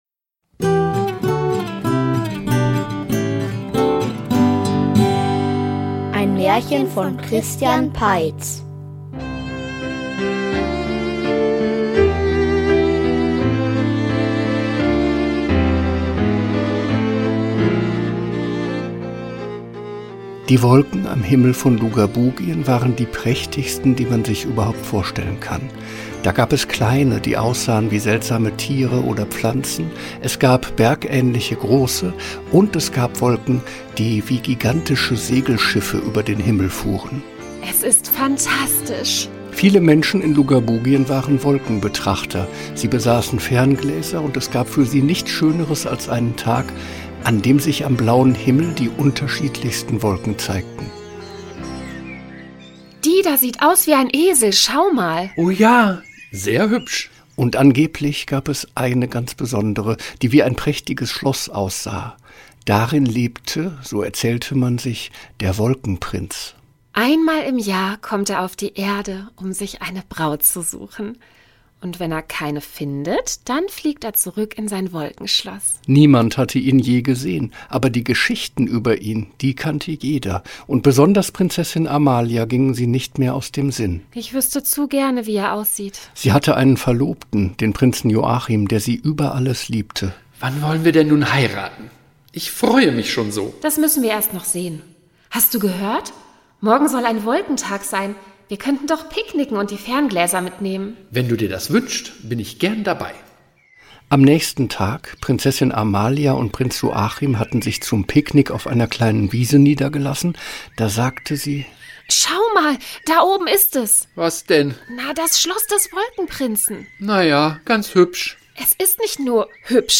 Der Wolkenprinz --- Märchenhörspiel #53 ~ Märchen-Hörspiele Podcast